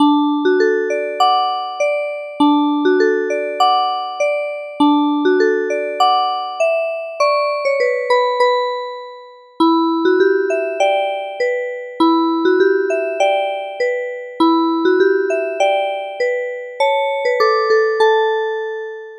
三拍子のおっとりしたBGM。